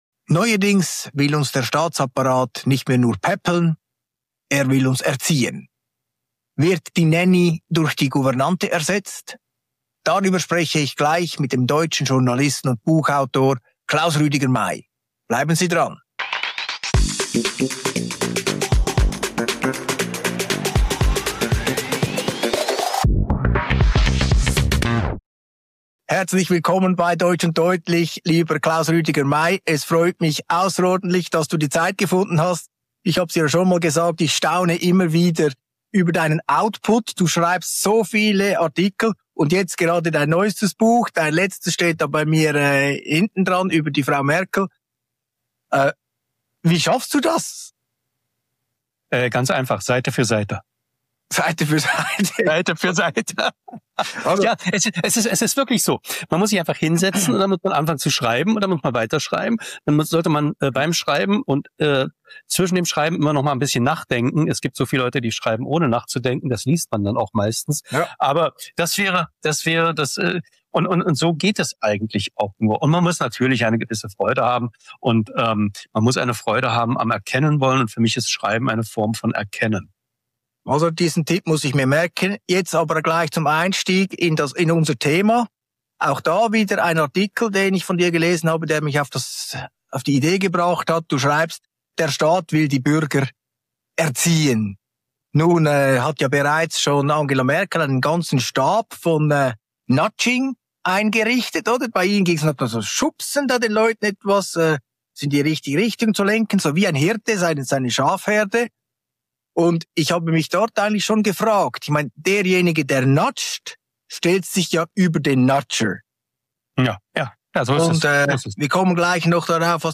Die Gesprächspartner diagnostizieren eine tiefgreifende Störung der Gewaltenteilung.